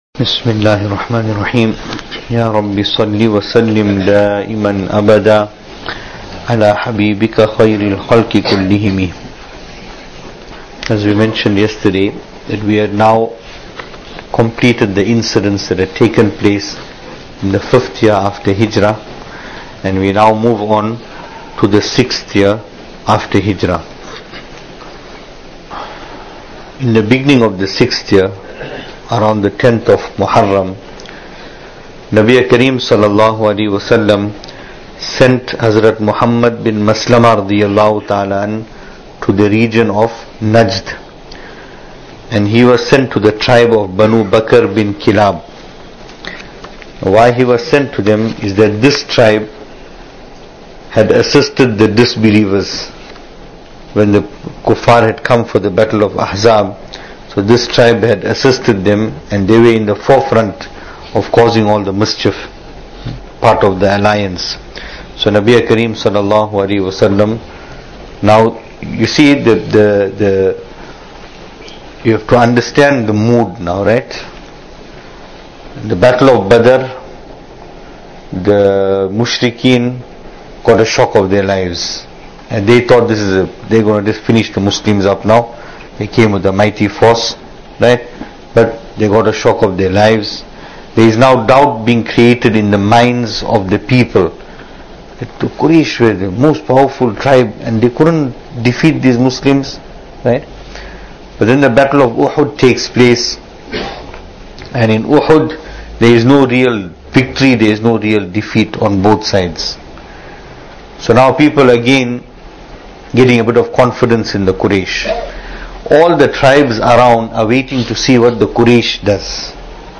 Venue: Masjid Taqwa, Pietermaritzburg | Series: Seerah Of Nabi (S.A.W)
Service Type: Majlis